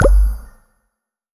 bubble-shimmer.wav